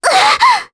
Ophelia-Vox_Damage_jp_01.wav